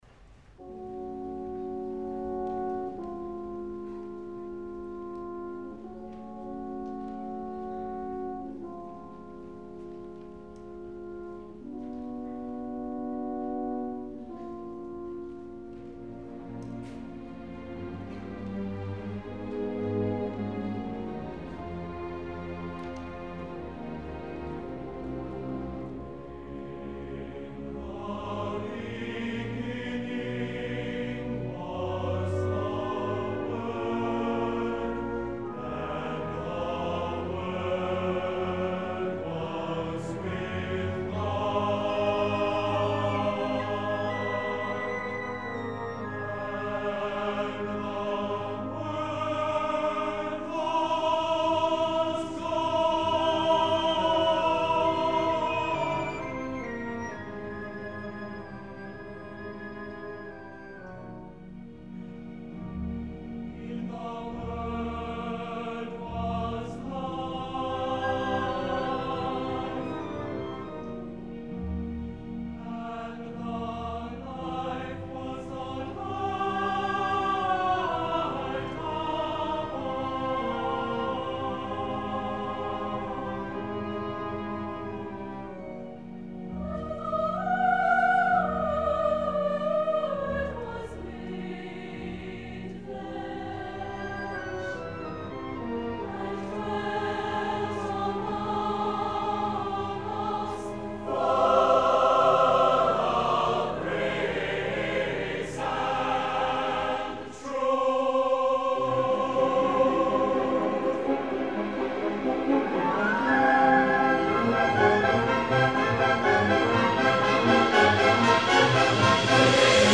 Listen to the St. Olaf Choir perform "Ring Out, Ye Crystal Spheres" by Ralph Vaughan Williams (1872-1958).